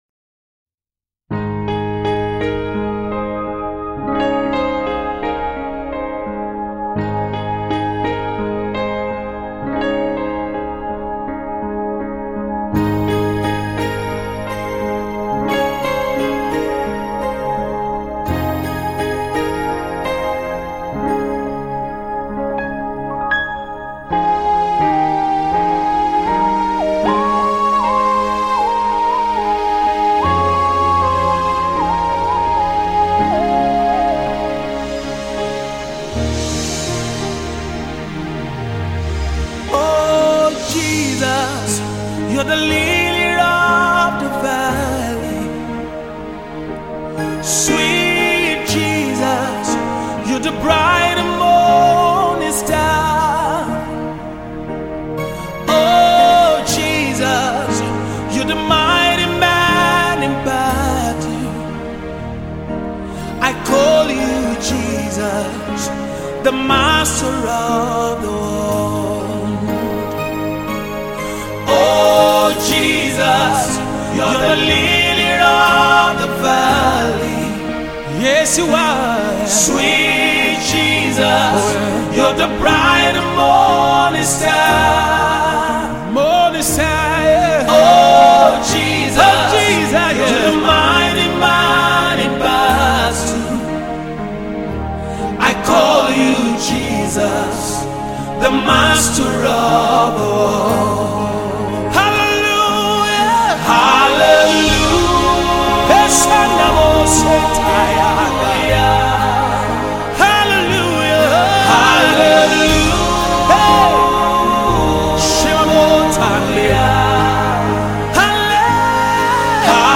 Spirit filled song